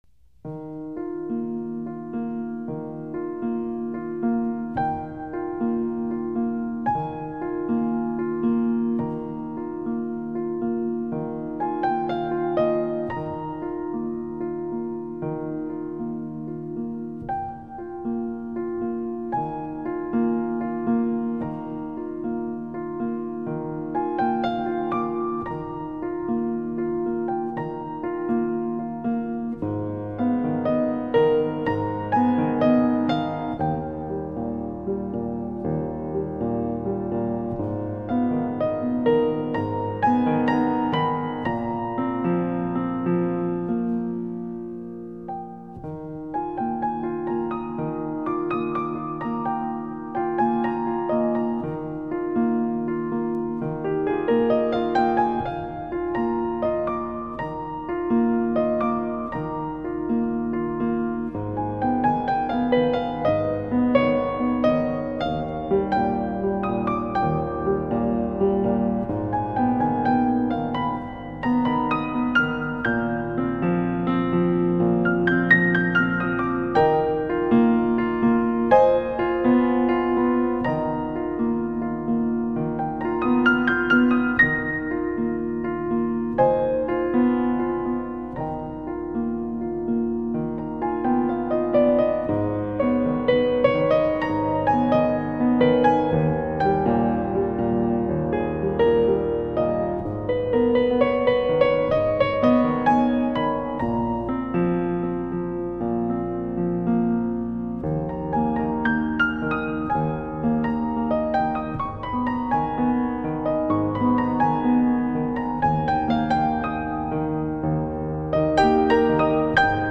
浪漫新世纪音乐
录音室：高雄亚洲数字录音室
浪漫钢琴演奏曲